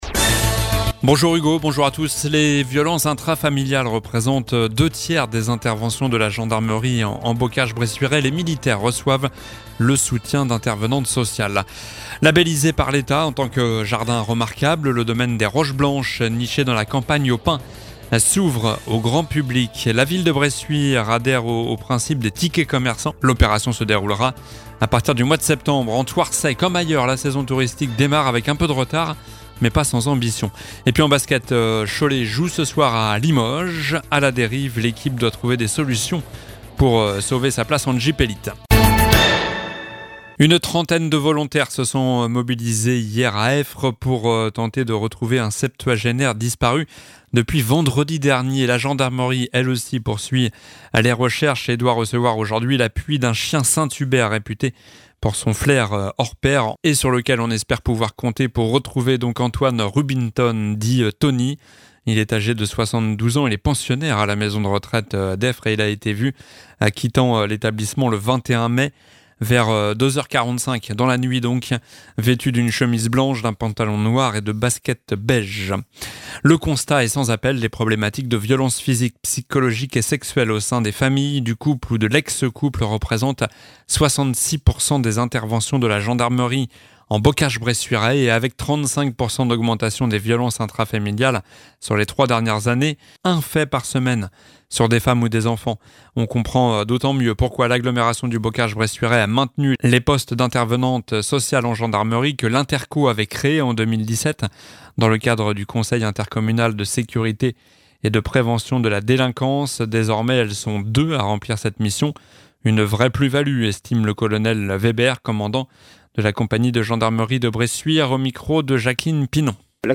Journal du mardi 25 mai (midi)